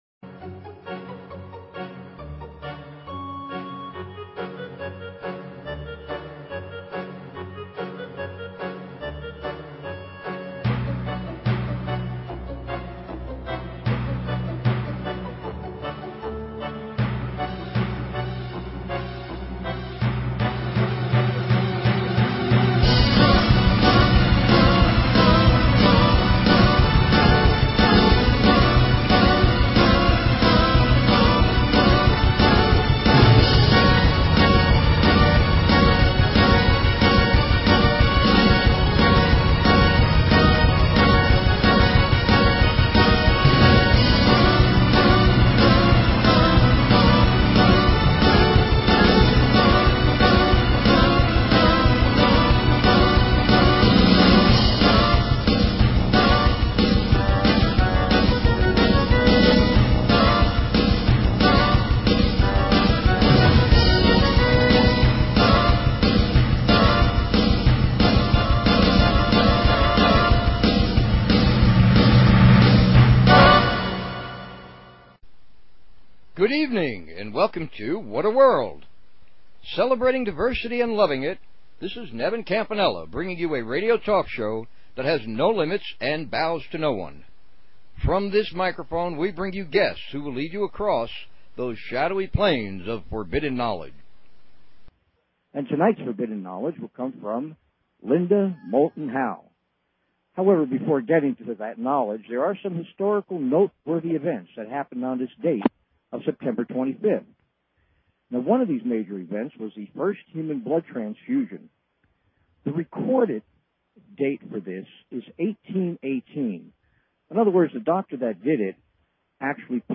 Talk Show Episode, Audio Podcast, What_A_World and Courtesy of BBS Radio on , show guests , about , categorized as
Live Show with guest Linda Moulton Howe